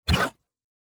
Next Vehicle Button Tap.wav